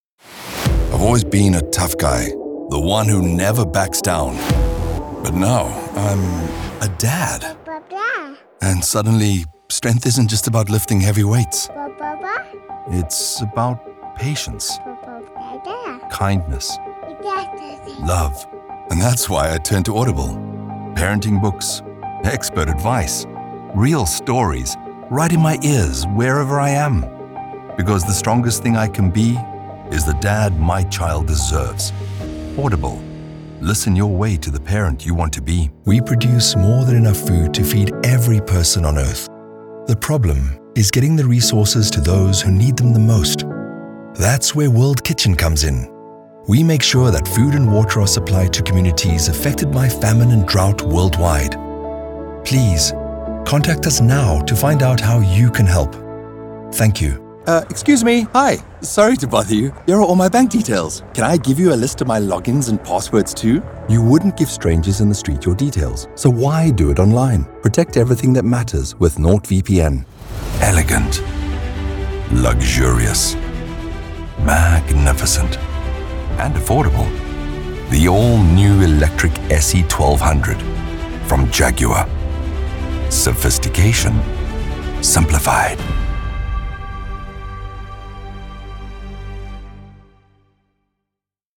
Englisch (südafrikanisch)
Vertrauenswürdig
Autorisierend
Warm